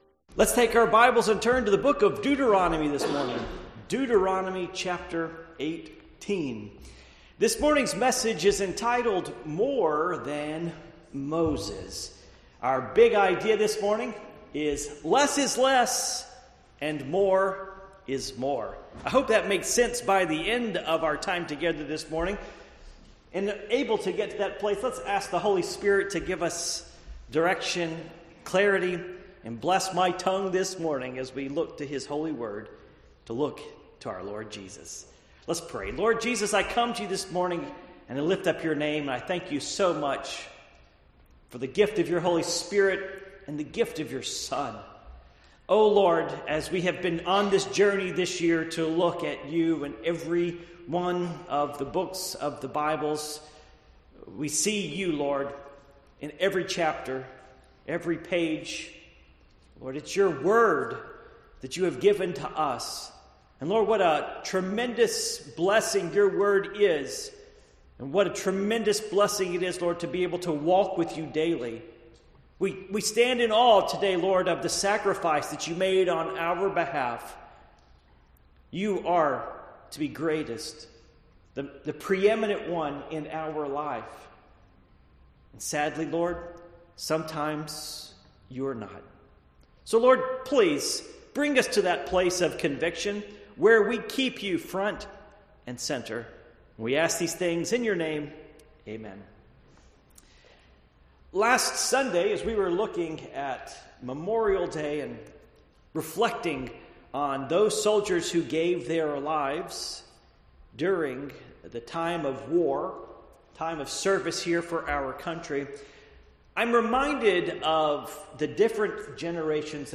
Passage: Deuteronomy 18:15-19 Service Type: Morning Worship